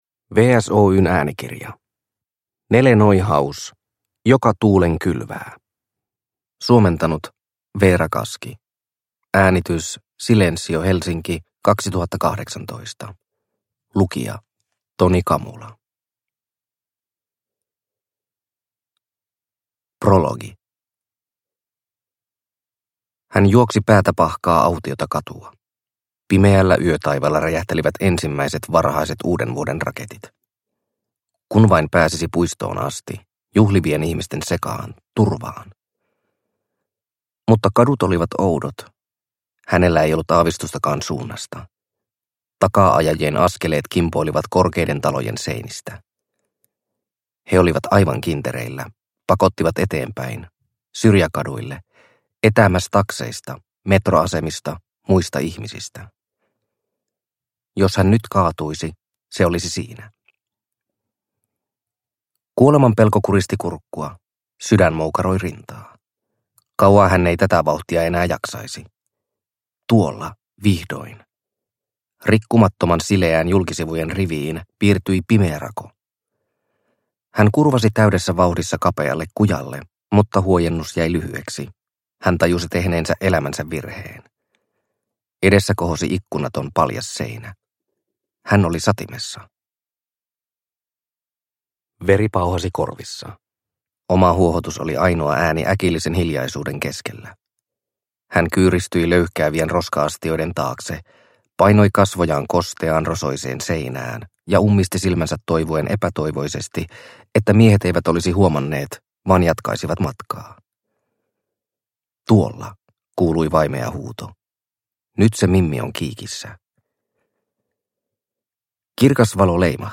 Joka tuulen kylvää – Ljudbok – Laddas ner